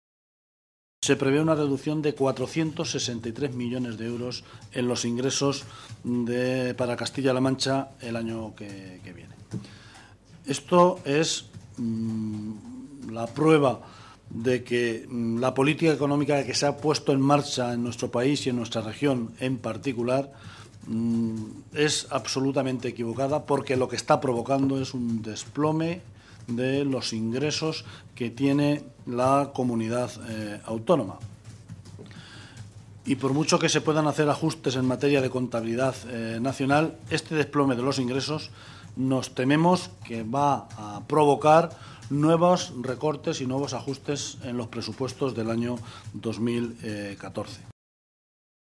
El portavoz del PSOE en el Parlamento regional se pronunciaba de esta manera esta mañana, en Toledo, en una comparecencia ante los medios de comunicación en la que detallaba las cifras que, hasta ahora, ha remitido el Ejecutivo de Cospedal al PSOE.
Cortes de audio de la rueda de prensa